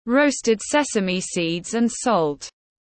Muối vừng tiếng anh gọi là roasted sesame seeds and salt, phiên âm tiếng anh đọc là /rəʊst ˈses.ə.mi siːd ænd sɒlt/